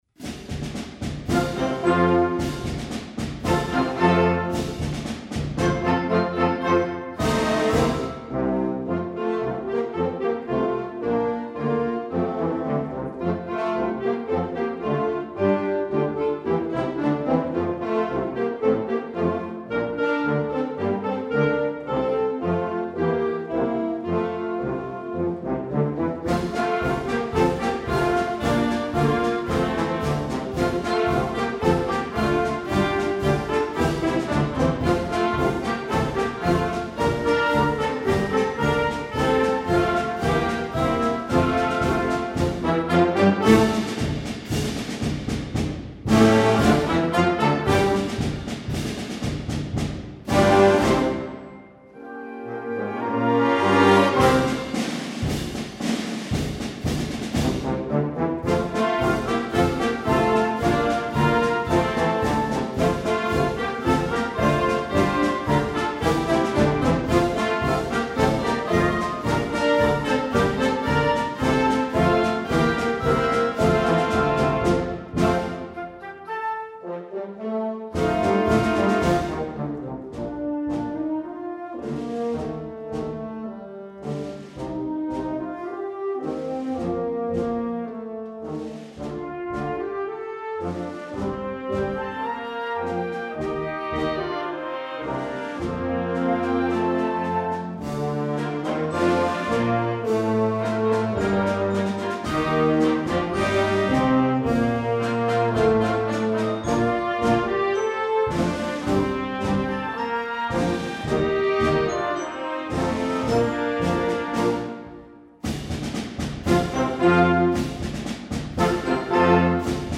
Gattung: Marsch für Jugendblasorchester
Besetzung: Blasorchester